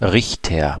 Ääntäminen
Ääntäminen Tuntematon aksentti: IPA: /ˈʀɪçtɐ/ IPA: /ˈrɪç.tər/ Haettu sana löytyi näillä lähdekielillä: saksa Käännös Ääninäyte Substantiivit 1. judge US Erisnimet 2.